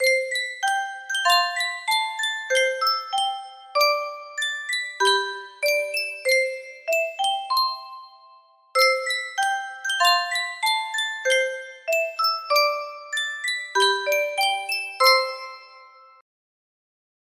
Sankyo Music Box - Gypsy Love Song YAH music box melody
Full range 60